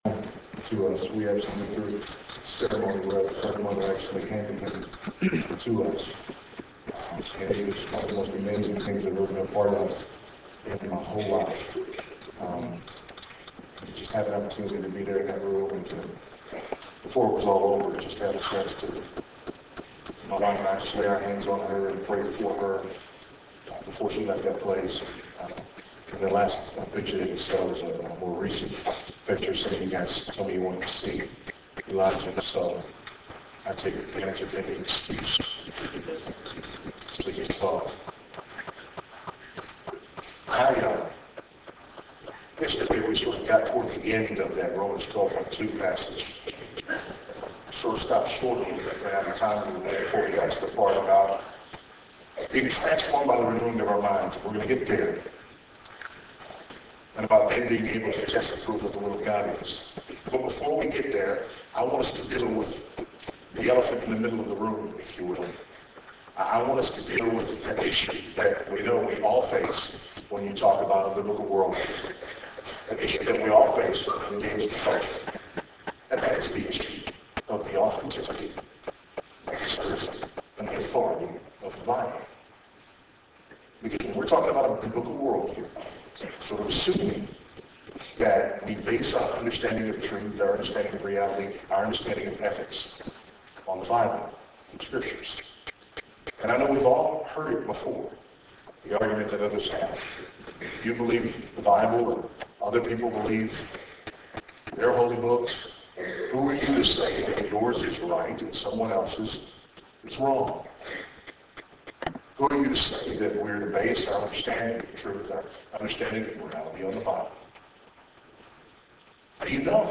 Faith in Practice Conference Session 2